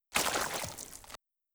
Flesh Hits